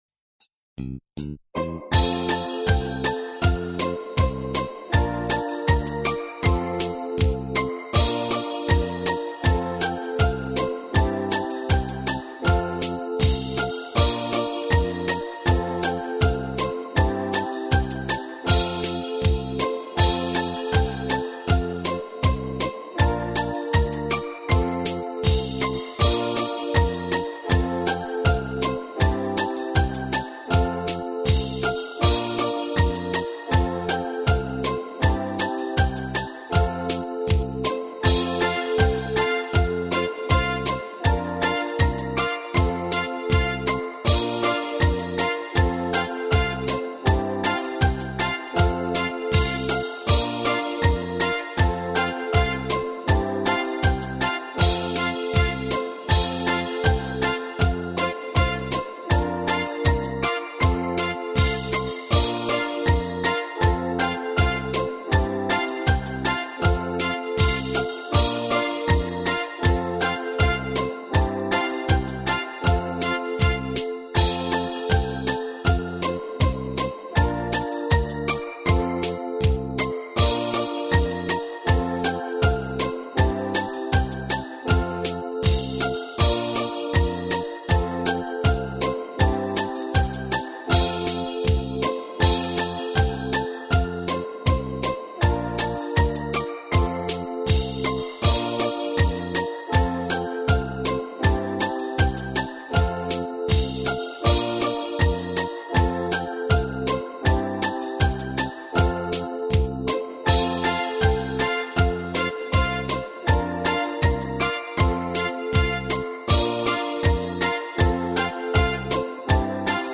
минусовка версия 12480